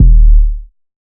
MURDA_KICK_SOUTHERN.wav